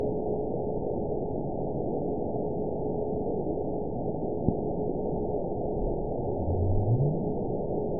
event 922223 date 12/28/24 time 12:25:26 GMT (11 months, 1 week ago) score 9.58 location TSS-AB04 detected by nrw target species NRW annotations +NRW Spectrogram: Frequency (kHz) vs. Time (s) audio not available .wav